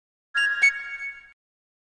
32. bagpipe